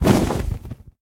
dragon_wings6.ogg